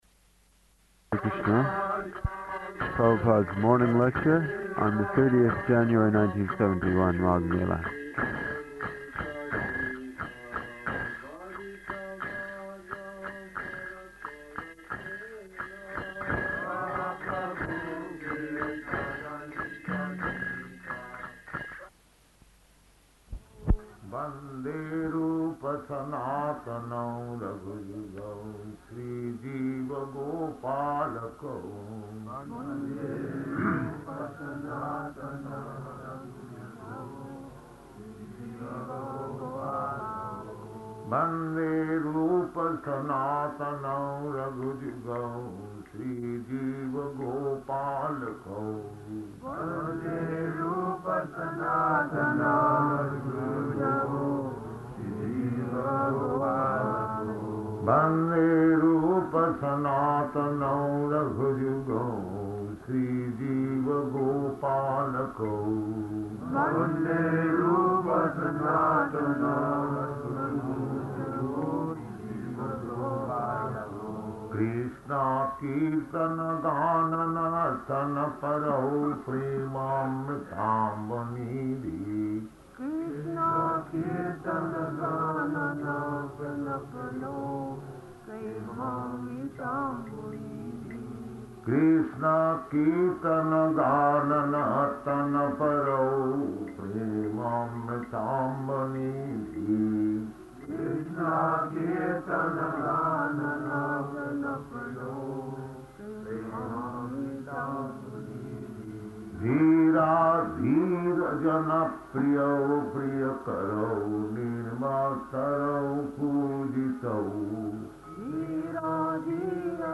Śrīmad-Bhāgavatam 6.2.46 Māgha-melā --:-- --:-- Type: Srimad-Bhagavatam Dated: January 30th 1971 Location: Prayaga Audio file: 710130SB-PRAYAGA.mp3 Devotee: [introducing recording] ...